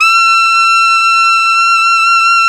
SAX ALTOMF0O.wav